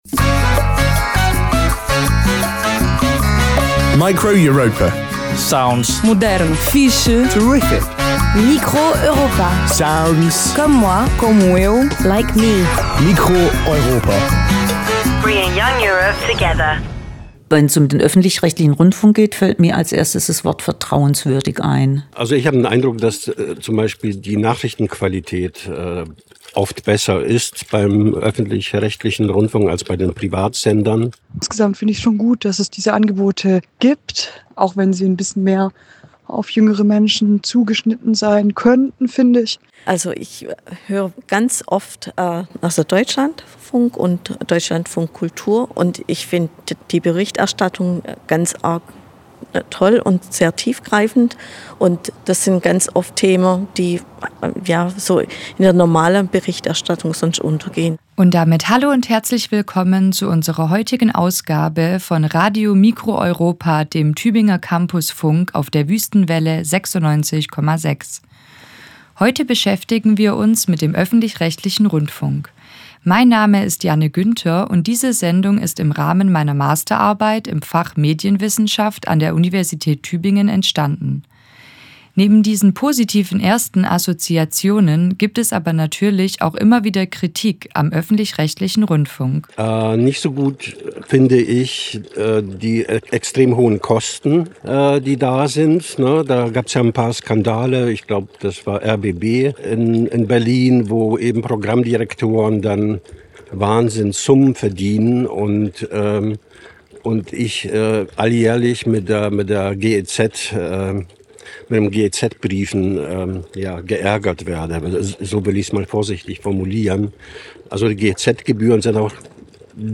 Zwischen Spardruck und Rechtsruck: Die Zukunft des ÖRR. Hörfunk-Feature
Form: Live-Aufzeichnung, geschnitten